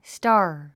発音 stɑ’ː r スター